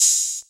Open Hat 1 [ 808 mafia ] (1).wav